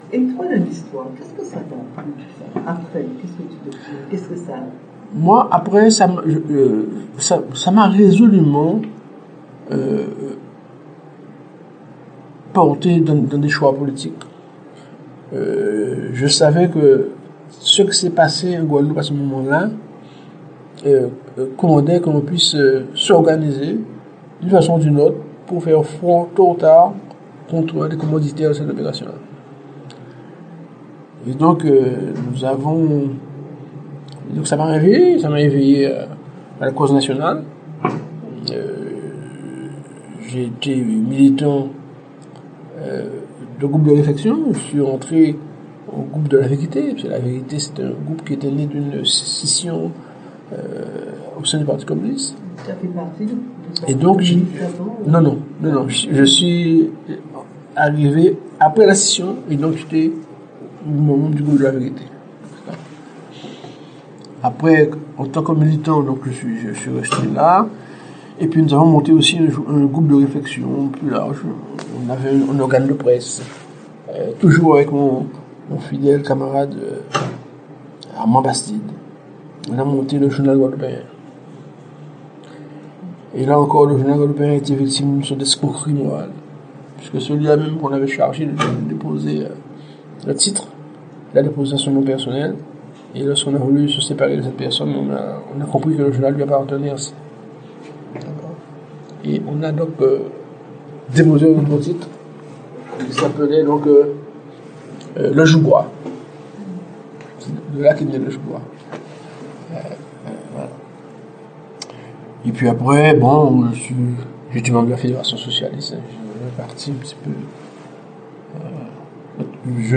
Intégralité de l'interview.